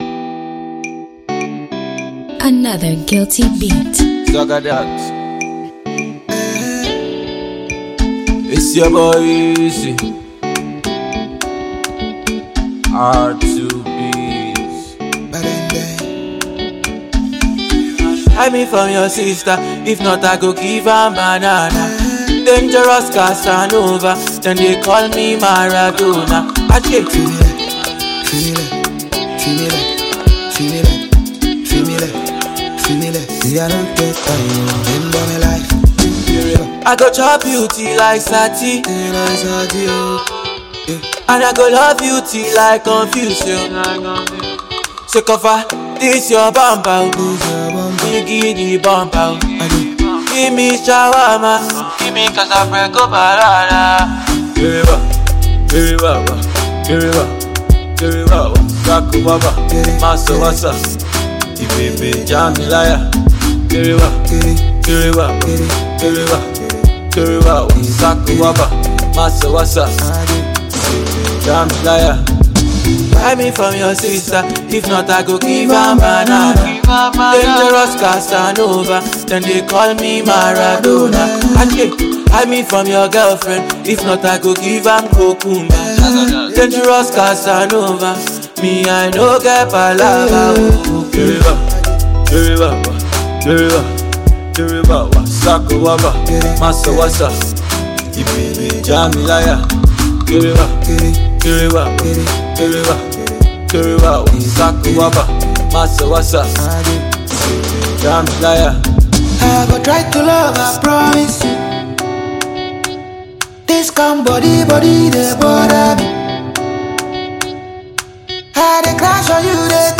Ghanaian multiple award-winning duo
award-winning Nigerian singer